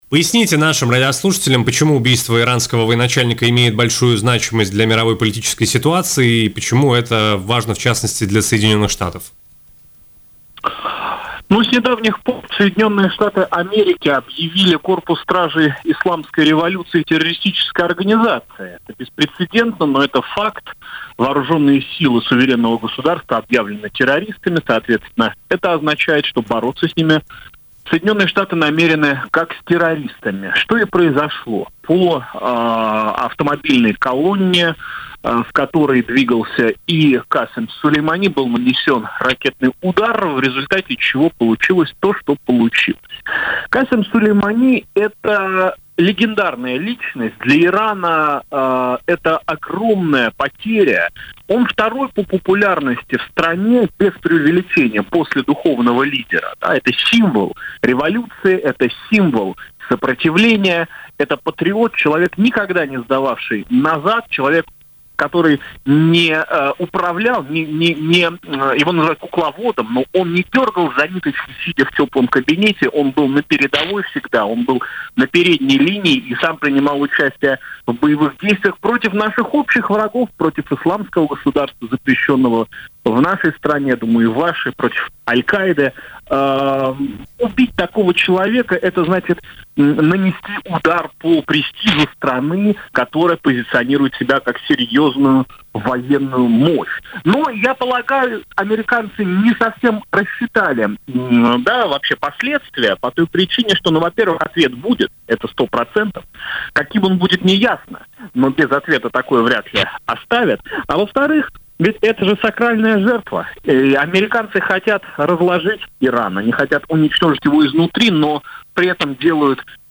Убийство в результате ракетного удара США главы спецподразделения «Аль-Кудс» Корпуса стражей исламской революции генерала Касема Сулеймани спровоцирует жестокий ответ Ирана в адрес Вашингтона.